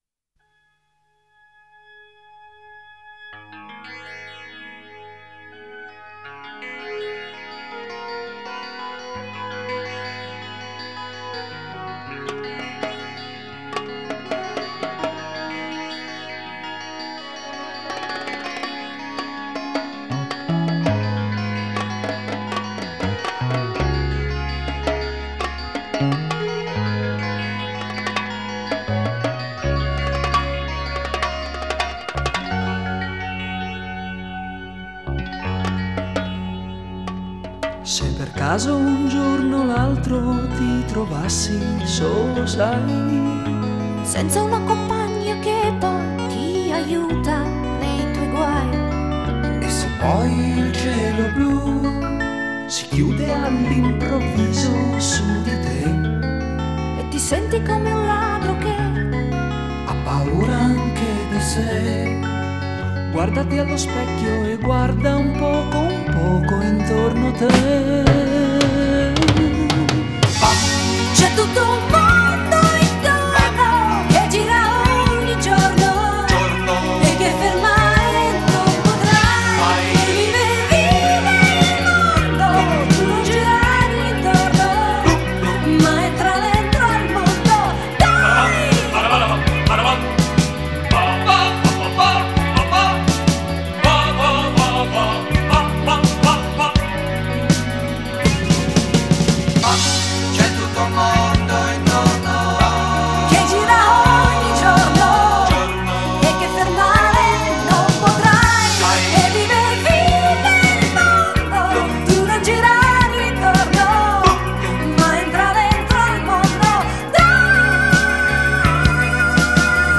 Жанр: Electronic. Synth-pop, Disco